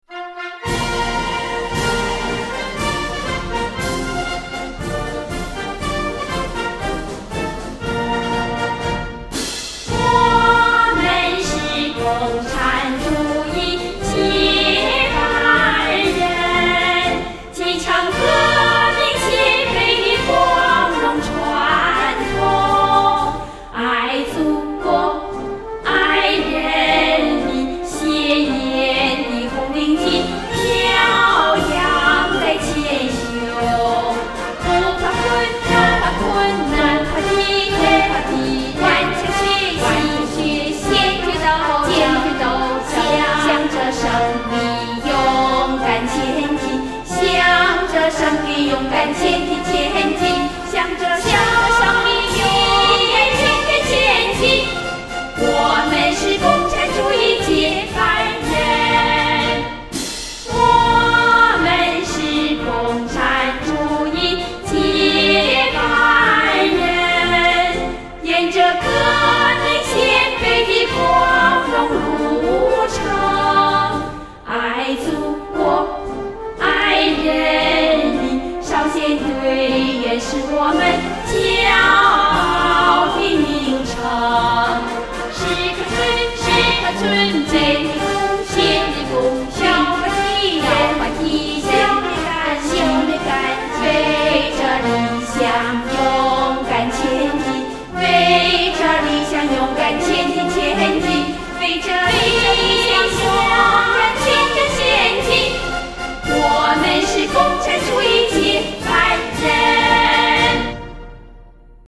这嫩“装”的比真的还嫩：）